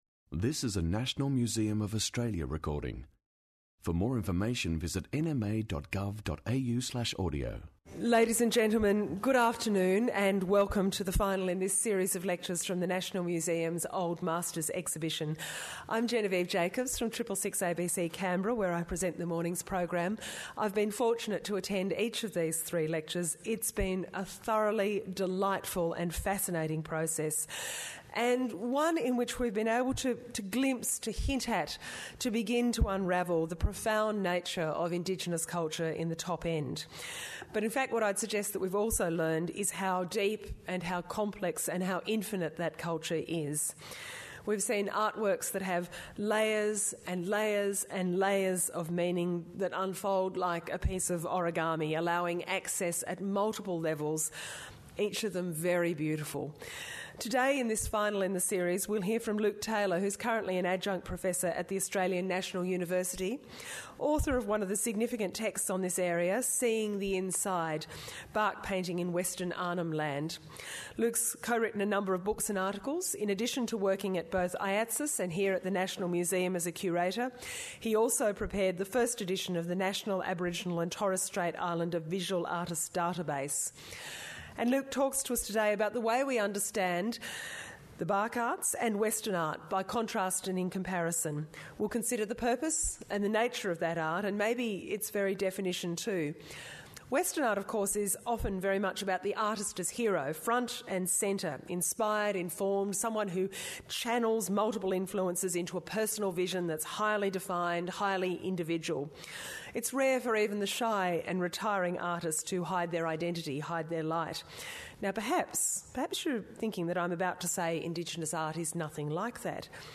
Old Masters lecture series 05 Jun 2014